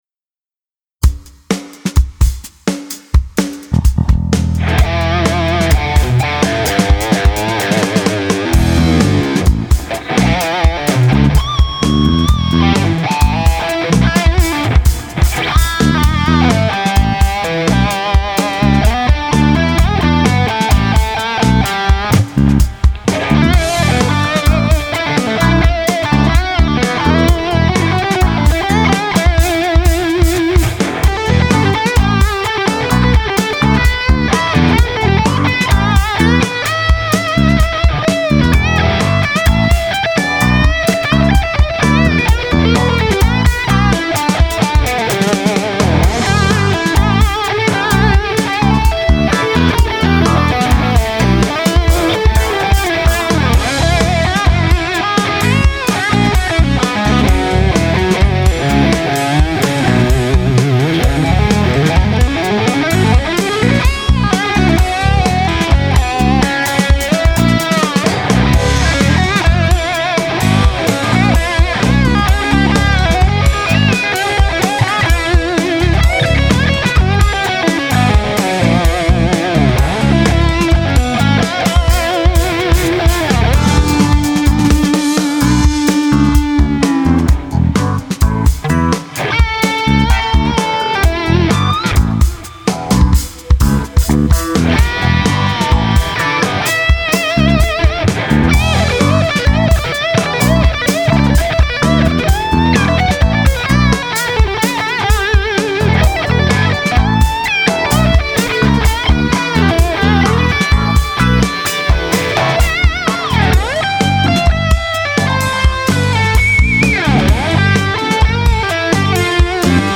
Ist meine 2008er American Standard Strat, Axe Boogie 4-Simulation mit Firmware 9 und saftig Gain. Ein bisschen Cubase Echo-Spielerei, weil ich es halt nicht lassen kann.